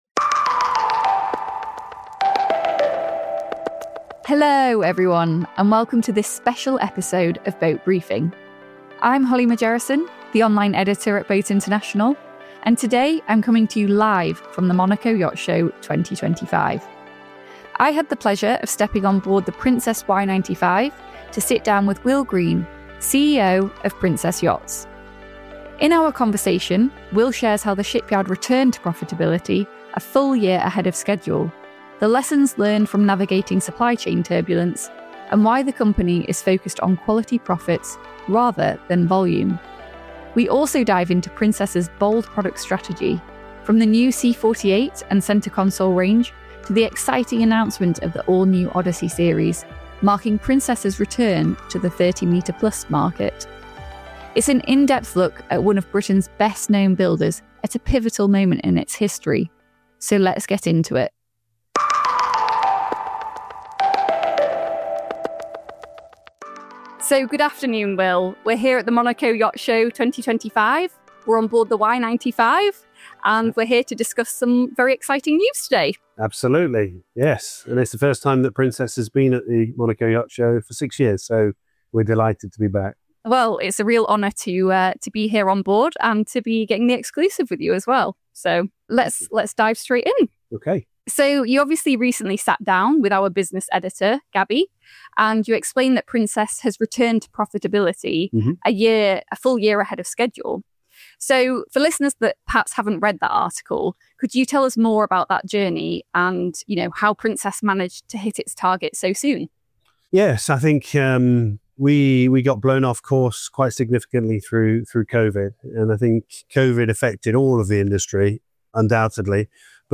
steps on board the Princess Y95 during the show for an exclusive chat